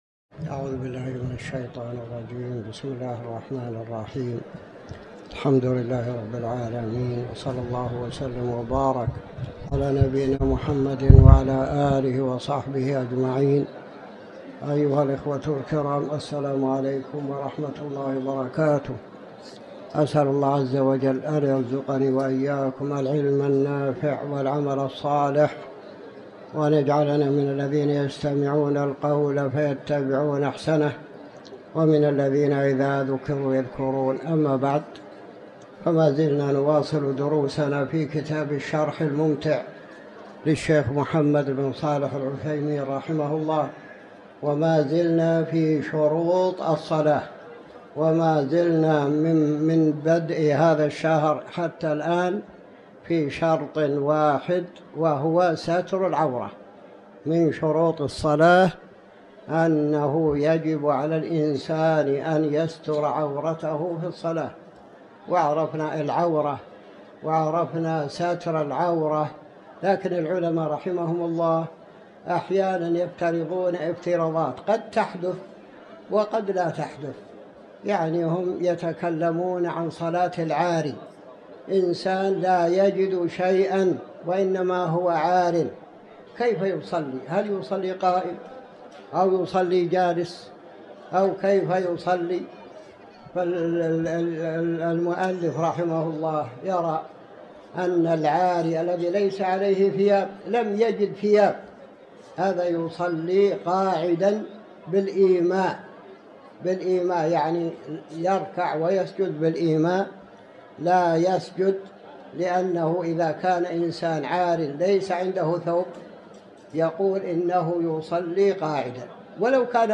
تاريخ النشر ٨ جمادى الآخرة ١٤٤٠ هـ المكان: المسجد الحرام الشيخ